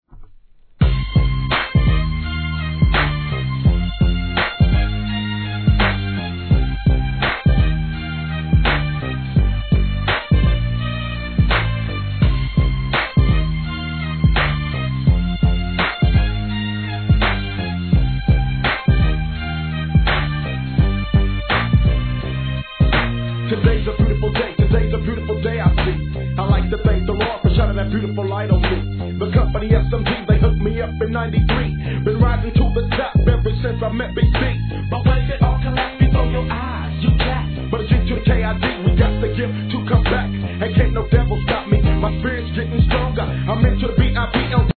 G-RAP/WEST COAST/SOUTH
通好みな極上FUNK仕上げの好EP盤が再入荷!!です!!